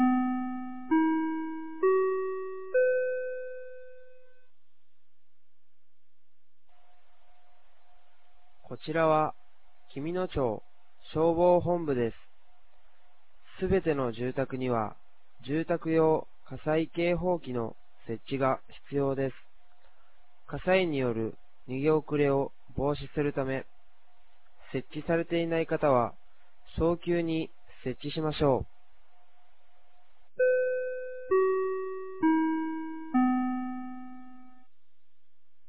2026年04月25日 16時00分に、紀美野町より全地区へ放送がありました。